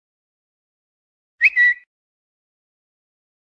เสียงแจ้งเตือน Twitter
เสียงแจ้งเตือน Twitter อย่างต่อเนื่อง เสียงข้อความเด้ง จะอึ๋ยย
หมวดหมู่: เสียงเรียกเข้า
am-thanh-thong-bao-twitter-th-www_tiengdong_com.mp3